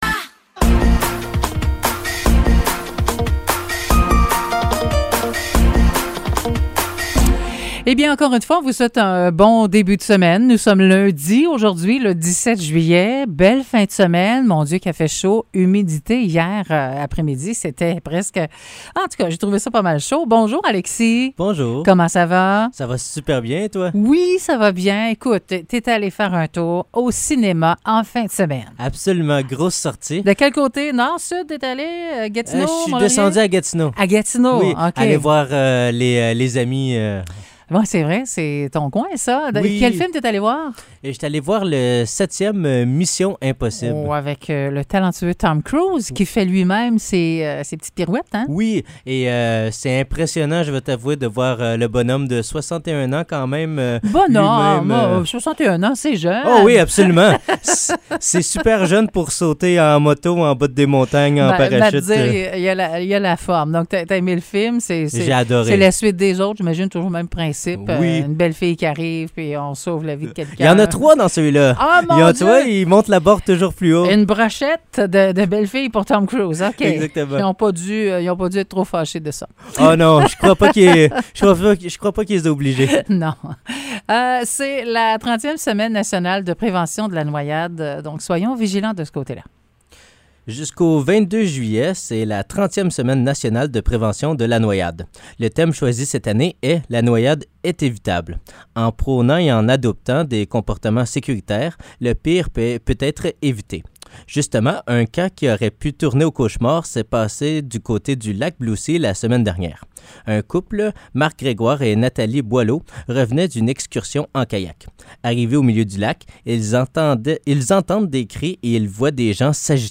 Nouvelles locales - 17 juillet 2023 - 9 h